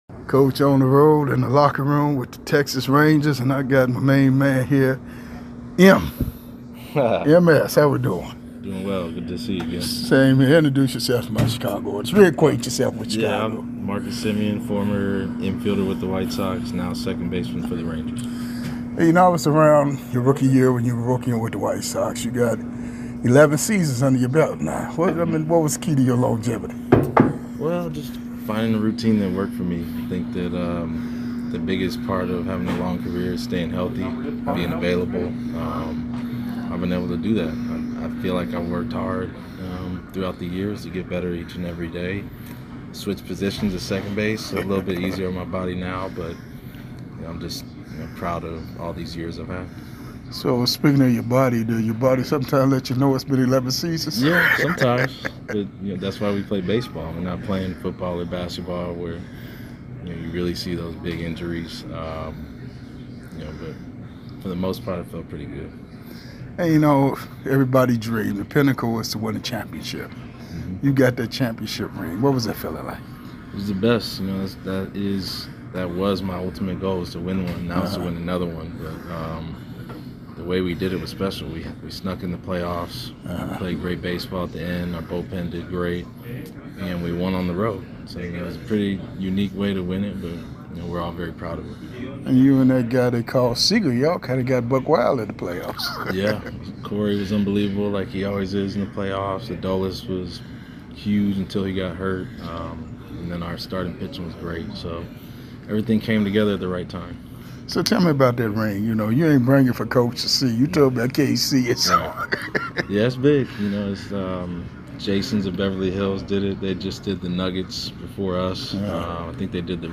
in-depth interviews with past and present MLB Stars of the game